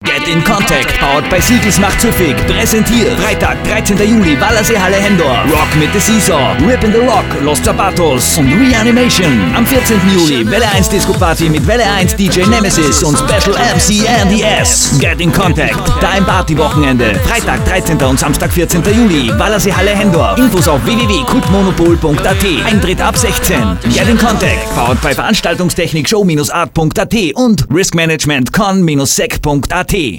live!!! 13.07.07 - Henndorf, Wallerseehalle